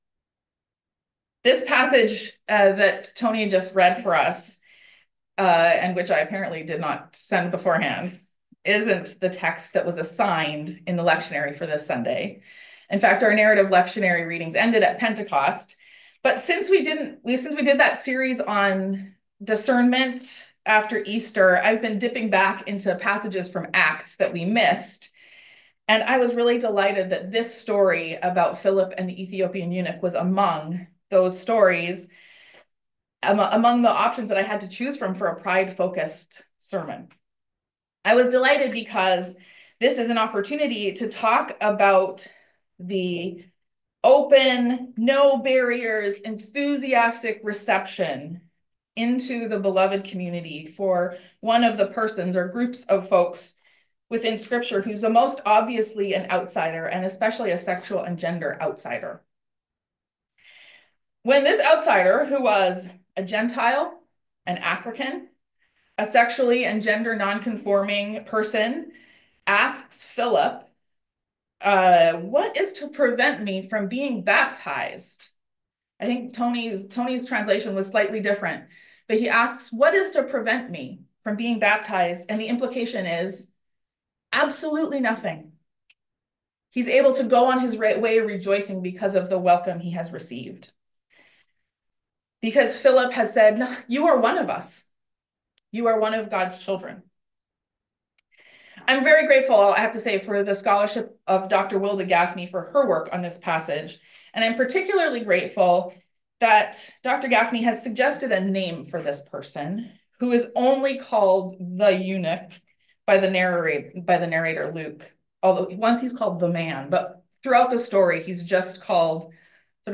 But since we did that series on discernment I’ve been dipping back into the Acts passages that we skipped and I was delighted that this story was one of the options to choose from for a Pride-focused sermon.